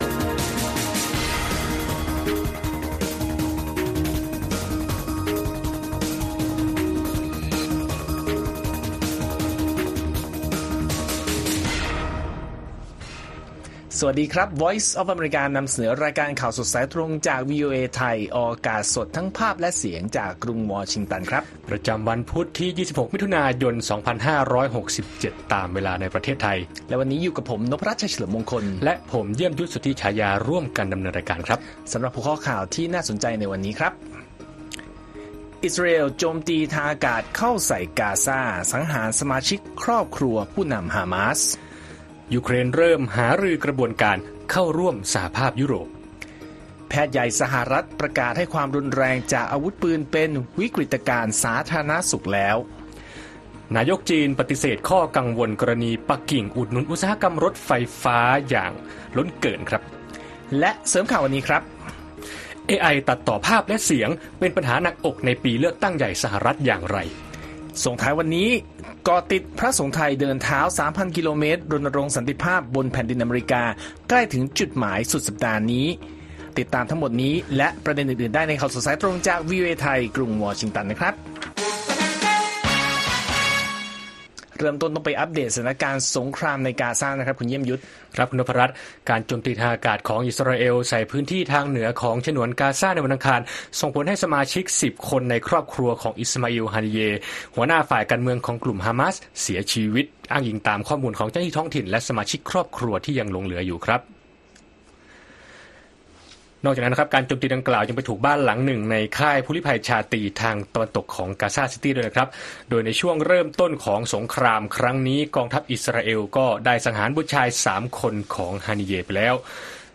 ข่าวสดสายตรงจากวีโอเอไทย 6:30 – 7:00 น. วันพุธที่ 26 มิถุนายน 2567